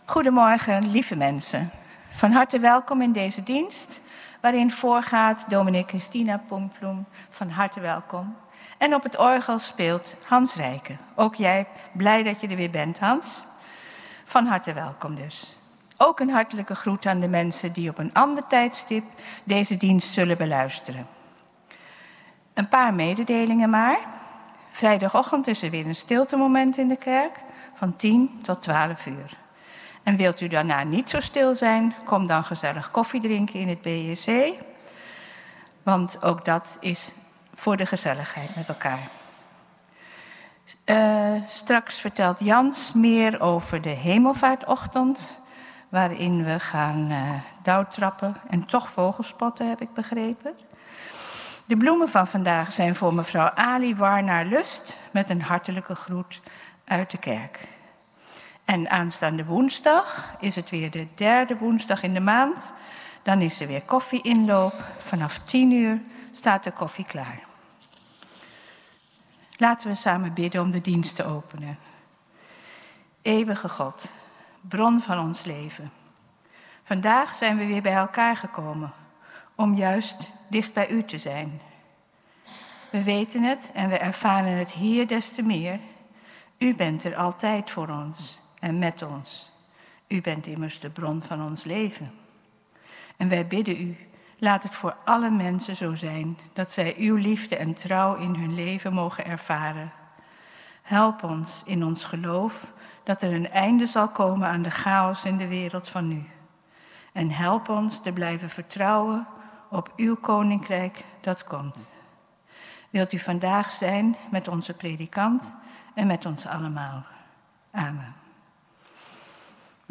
Kerkdienst geluidsopname 3 augustus 2025